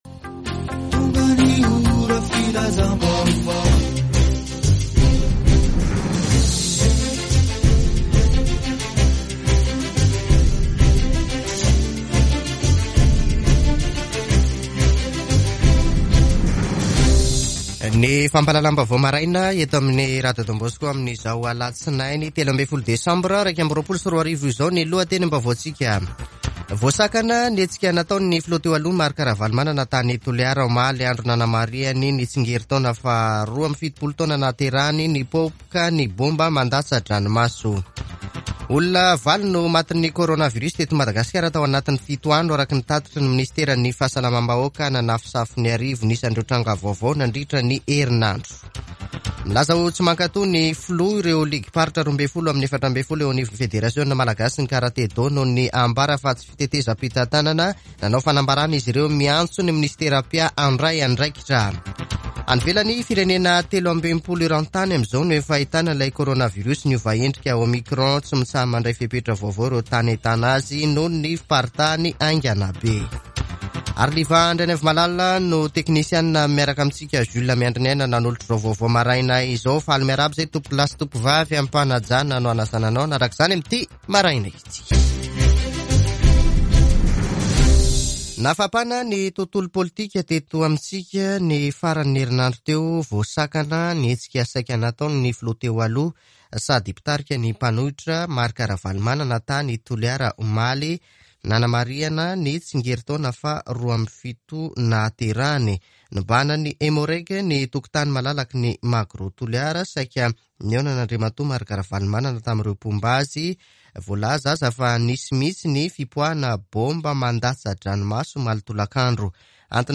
[Vaovao maraina] Alatsinainy 13 desambra 2021